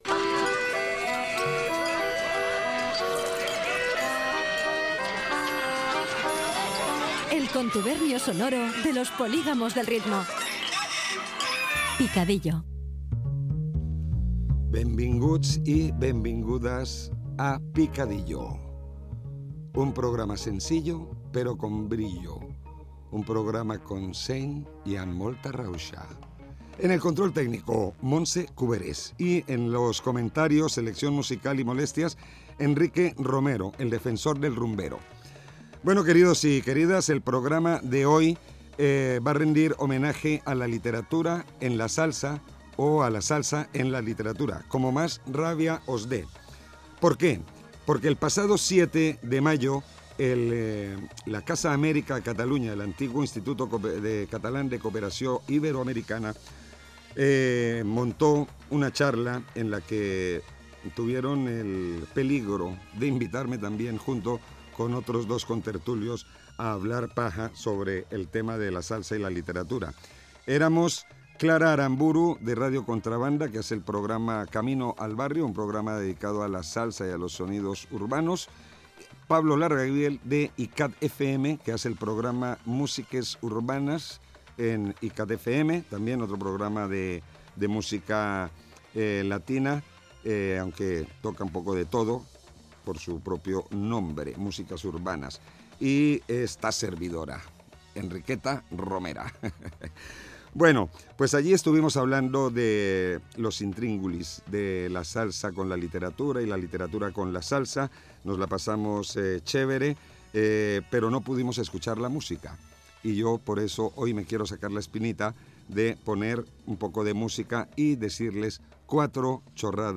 Careta del programa, salutació, equip, espai dedicat a la literatura i la salsa, a partir d'una jornada feta a Casa Amèrica de Barcelona
Musical